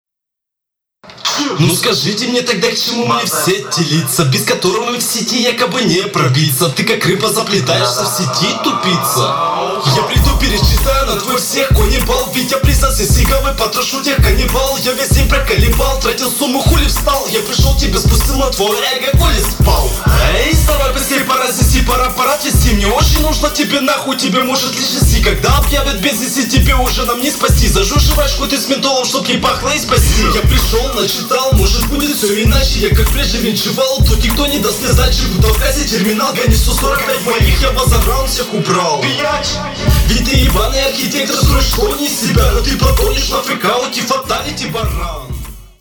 Вообще неслушабельно из-за звука.
флоу потешный в общем-то, но эти рифмы все портят
Это не лайв-баттл, чтобы начинать с акапеллы. Подача невыразительная, разговариваешь.